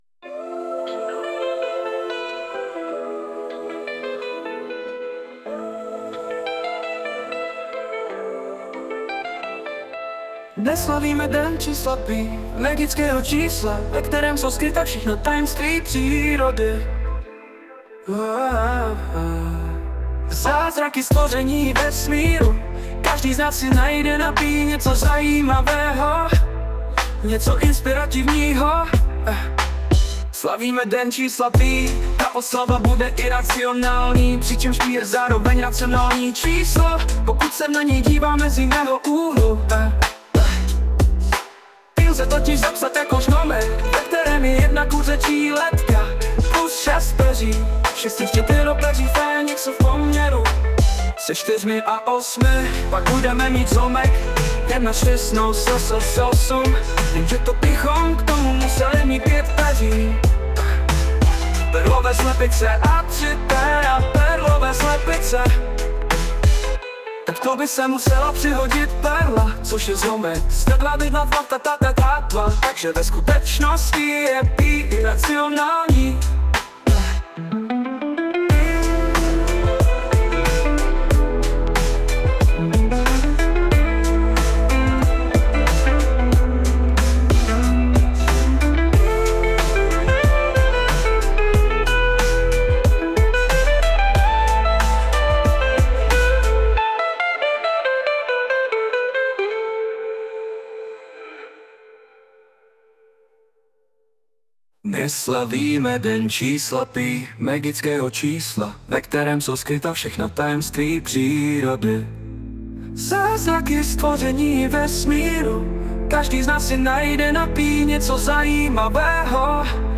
Tento rok jsme do oslav dne pí zapojili i umělou inteligenci.